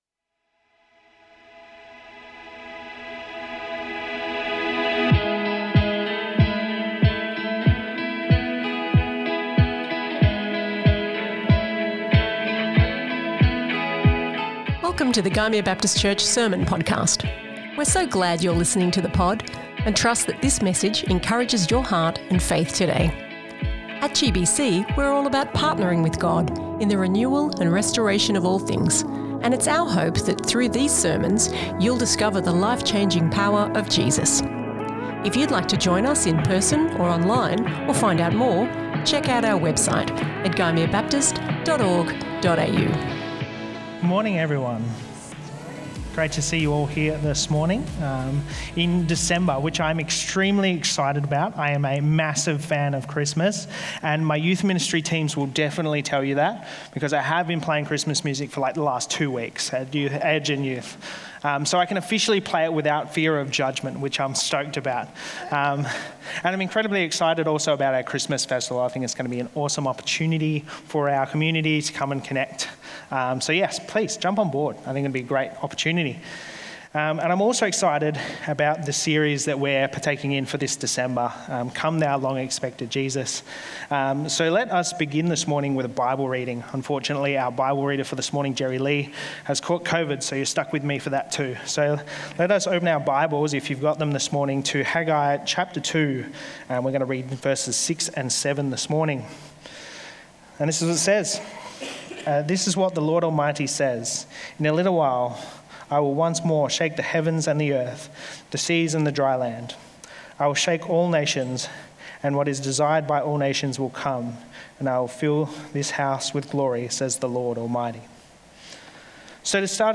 This is the first sermon in our new Advent series, Come Thou Long Expected Jesus.